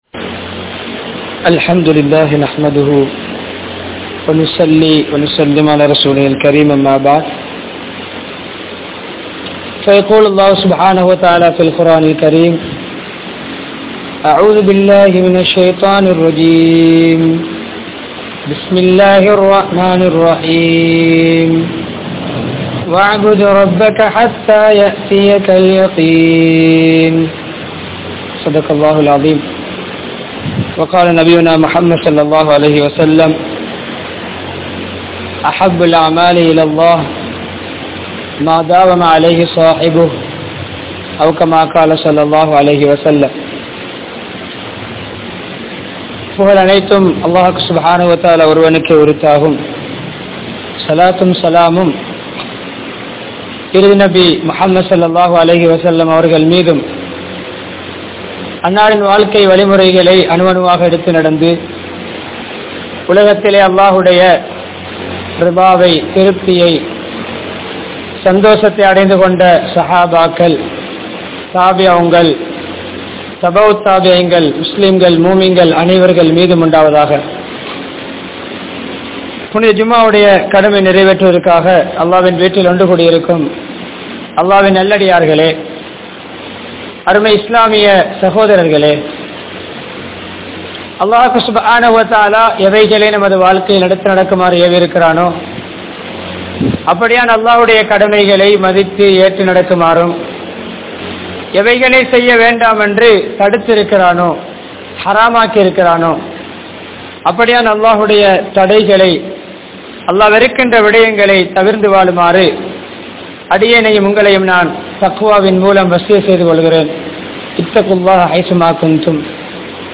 Ramalaanukku Pin Evvvaaru Vaalvathu? (ரமழானுக்கு பின் எவ்வாறு வாழ்வது?) | Audio Bayans | All Ceylon Muslim Youth Community | Addalaichenai
Kelaniya, Gonawala Jumua Masjidh